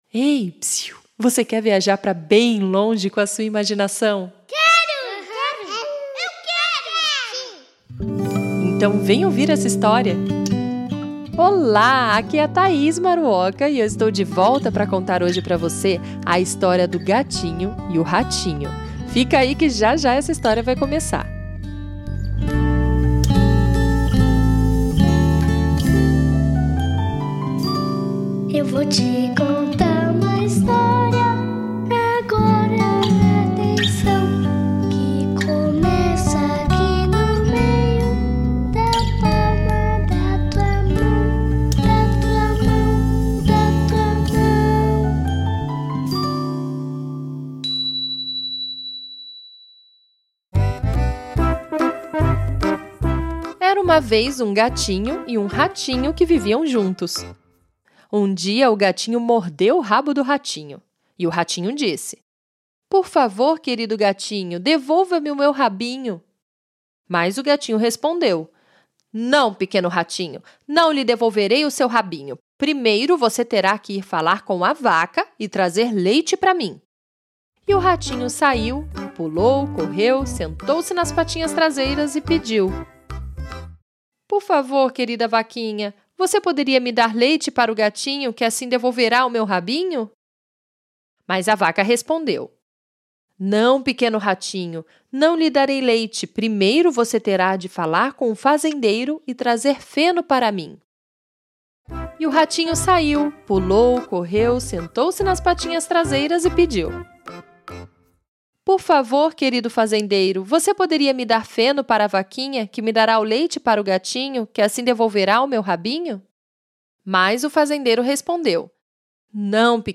É um conto rítmico inglês, super indicado para as crianças menores.